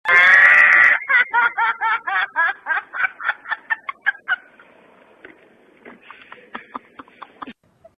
Risada Mulher Banguela
Gargalhada de mulher banguela em reportagem na televisão
risada-mulher-banguela.mp3